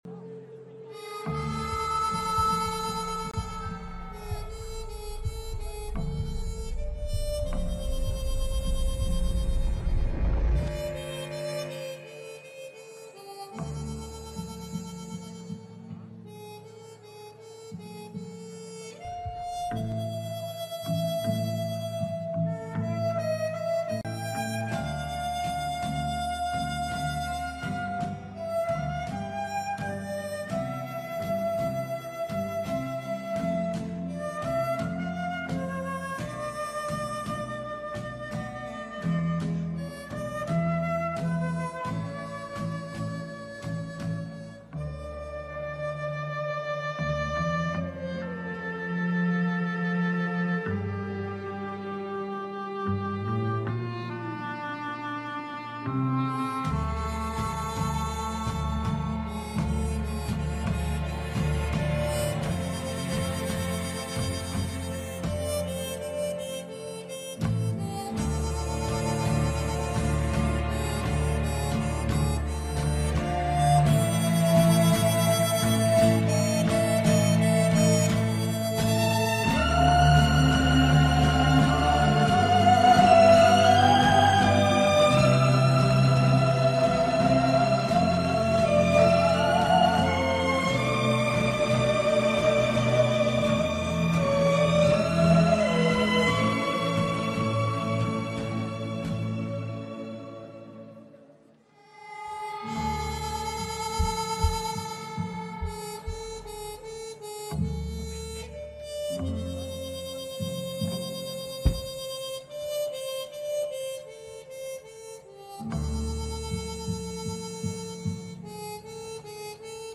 French Indie Band